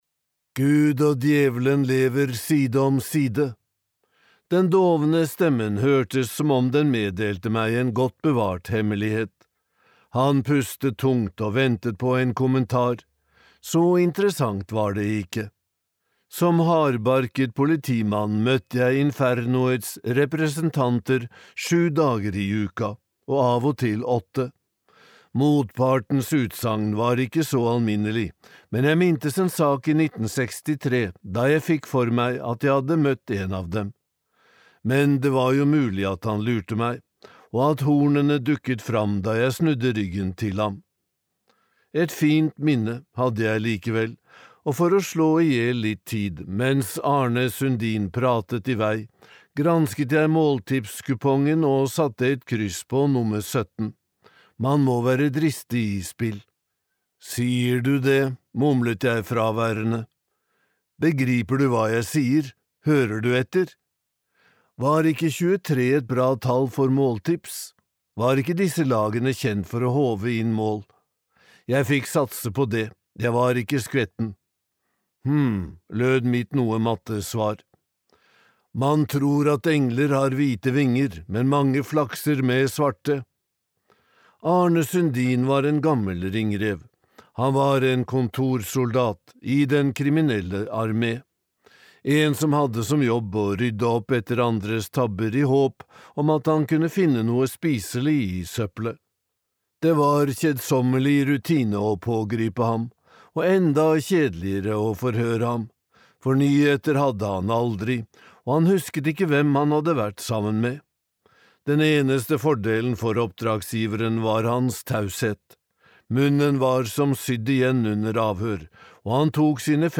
Plyndrerne (lydbok) av Olov Svedelid